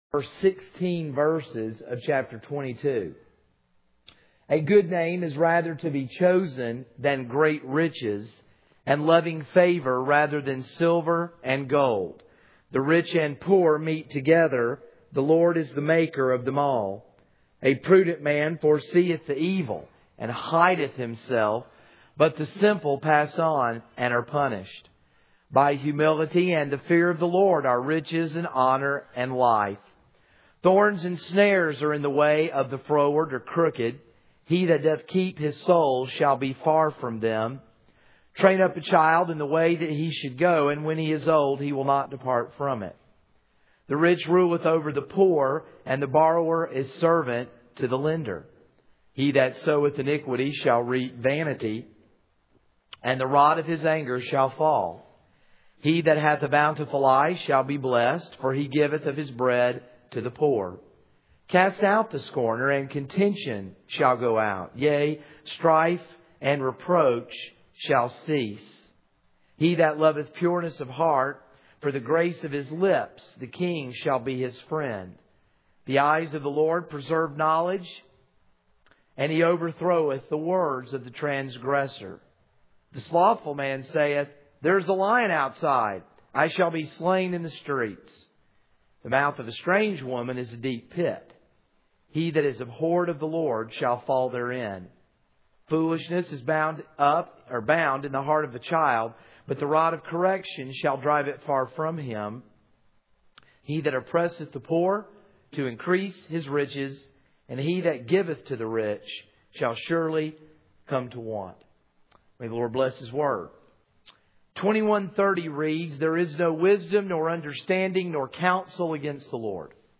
This is a sermon on Proverbs 22:1-16.